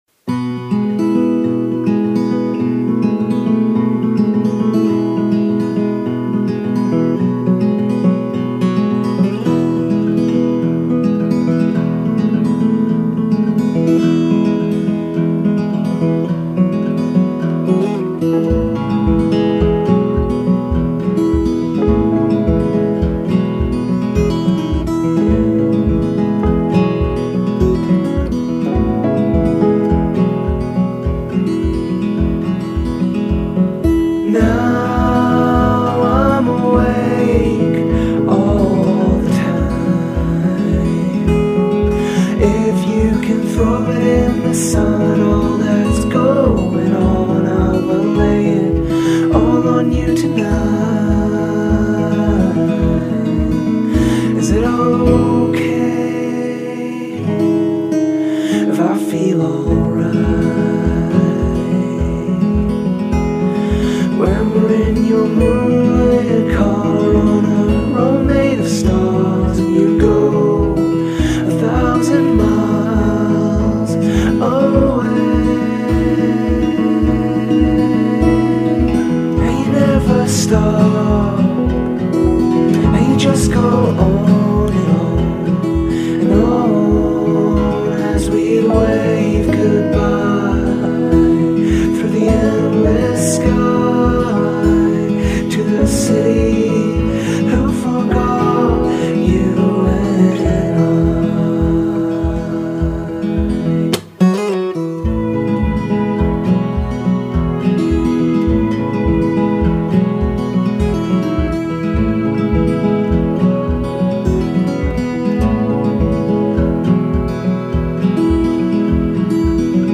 singer-songwriter
This album is a beautifully constructed acoustic album.
soft, smooth guitar picking
warm, whispered and hypnotic vocals